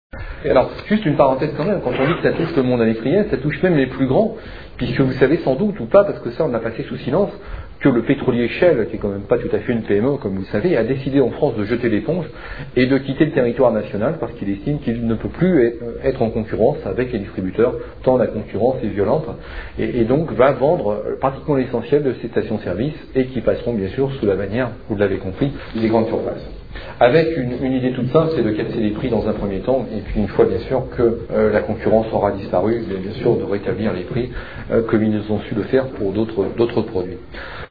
Conférence tenue le 18 août 2004 à Montpellier